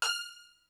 STR HIT F5.wav